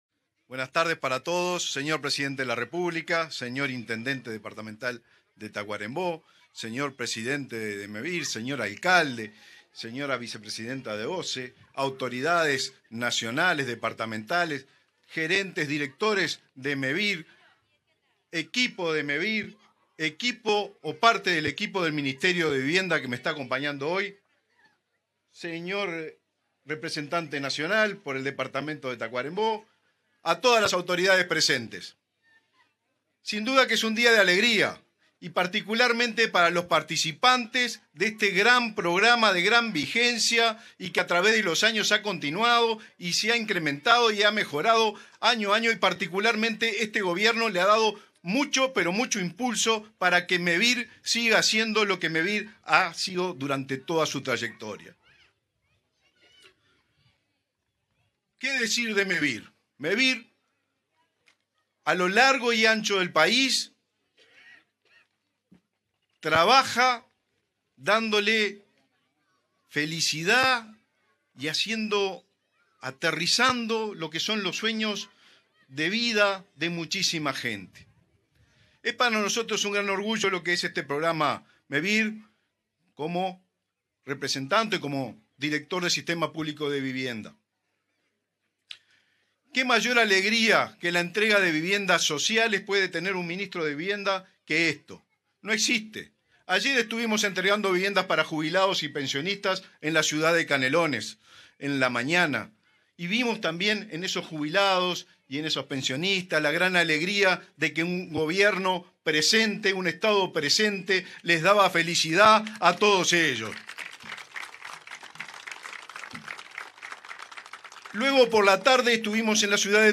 Palabras del ministro de Vivienda, Raúl Lozano
Palabras del ministro de Vivienda, Raúl Lozano 26/07/2024 Compartir Facebook X Copiar enlace WhatsApp LinkedIn En el marco de la entrega de 47 soluciones habitacionales de Mevir construidas en madera, en el departamento de Tacuarembó, este 26 de julio, se expresó el ministro de Vivienda y Ordenamiento Territorial (MVOT), Raúl Lozano.